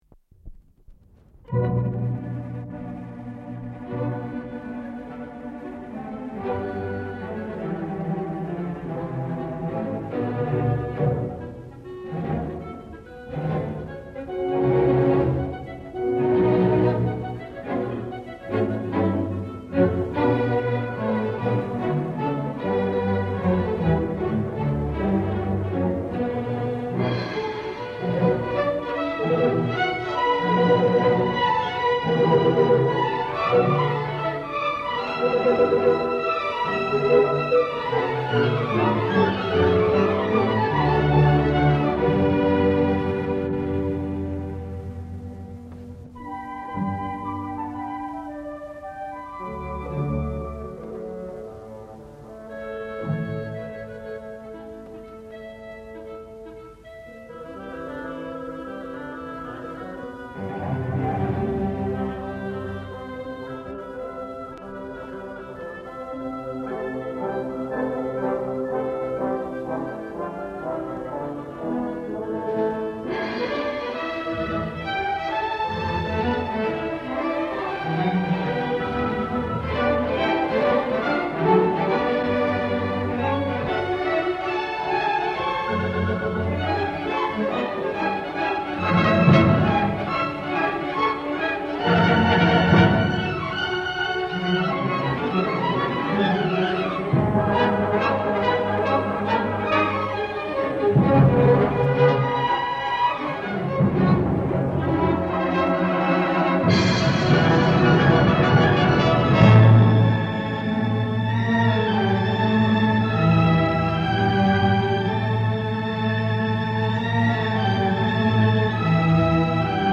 Poème symphonique N°1